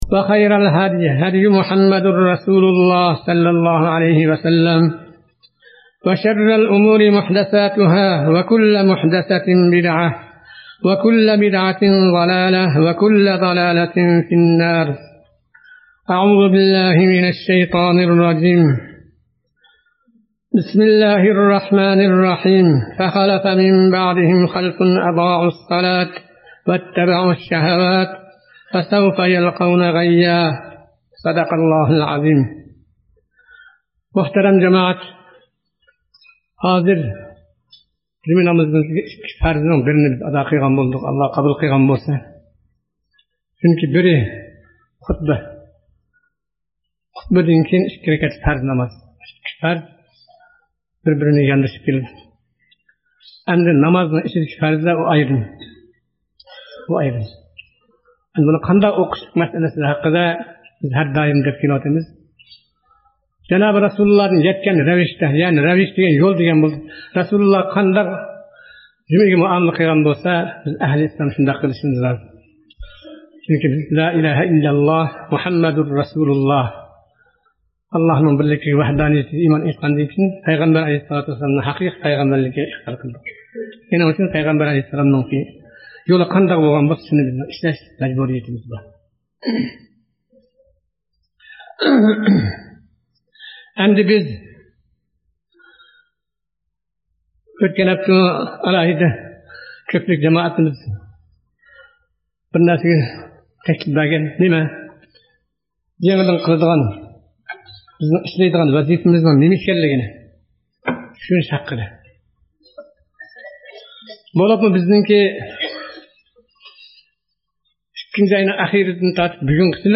نامازنىڭ ئەھمىيىتى توغرىسىدىكى، بۇ لېكسىيە مەرھۇم ئاب